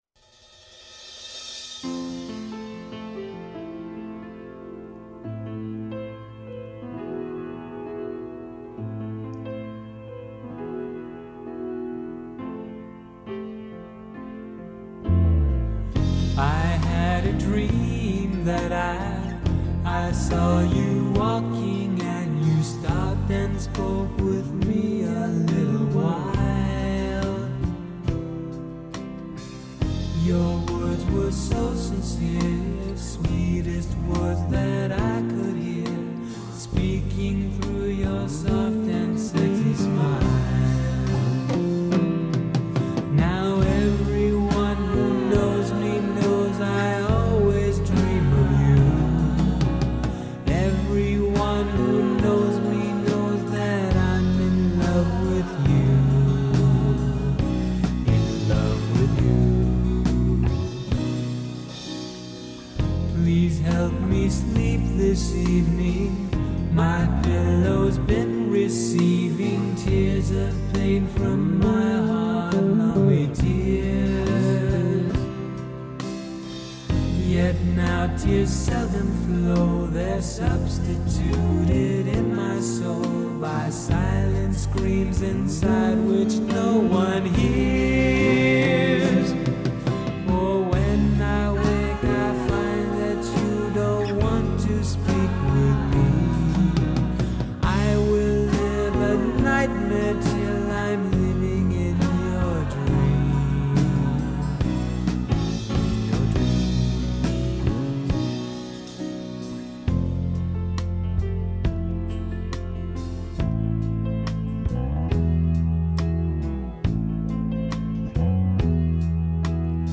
(back-track without lead vocal)
bass guitar
accoustic guitar